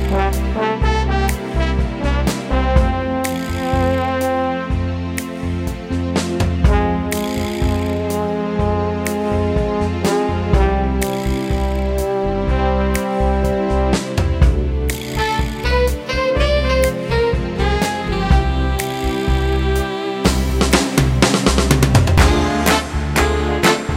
No Guitars Crooners 3:25 Buy £1.50